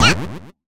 jump.ogg